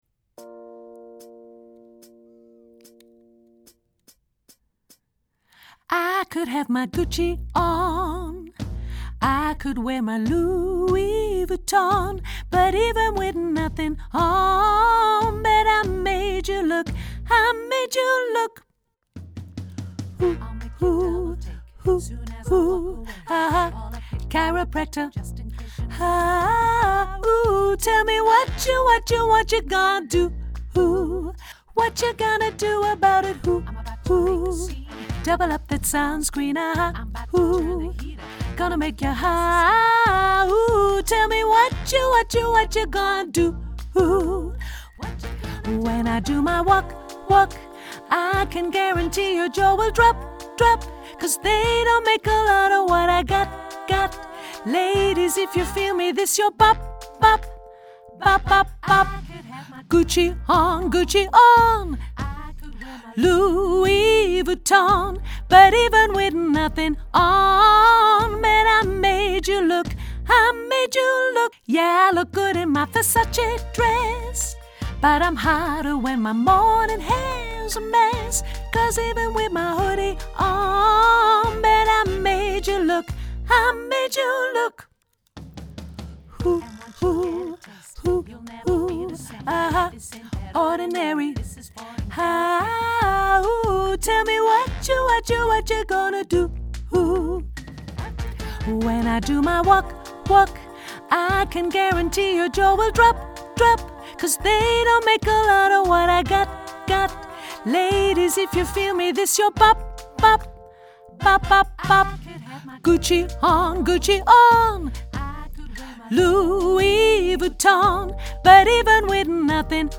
mezzo sopraan